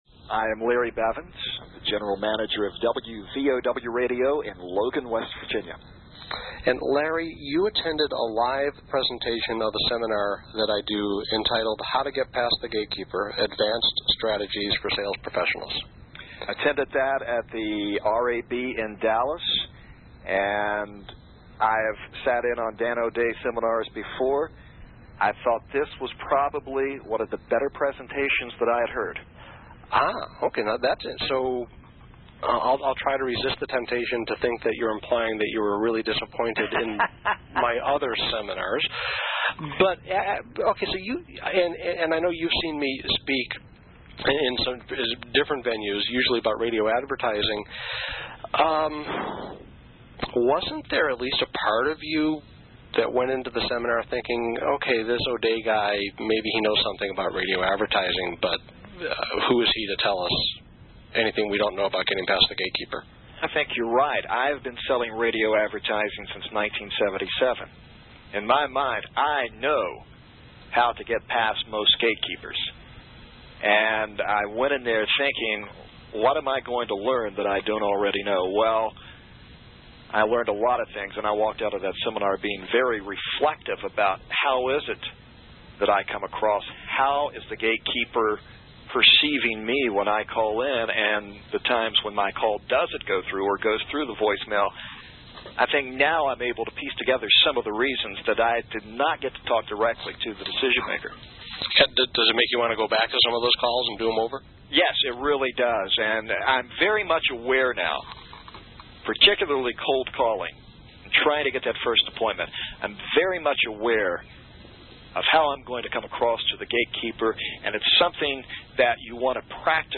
Hear How Other Salespeople Have Successfully Used These Methods To Reach The Decision-Maker Effortlessly!